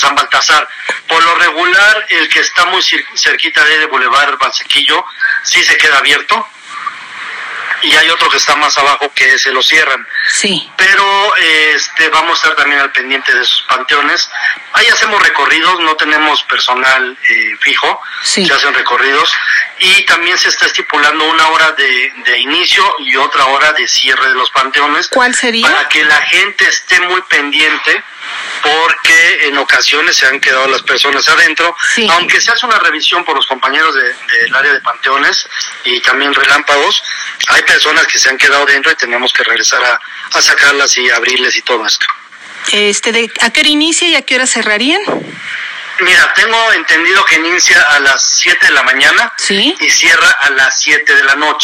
En entrevista con Efekto 10 Noticias, el funcionario municipal acotó que de manera coordinada se implementarán acciones en el Panteón Municipal con Seguridad Ciudadana, DIF, Servicio Operador de Limpia, Servicios Públicos, Bomberos, entre otras dependencias.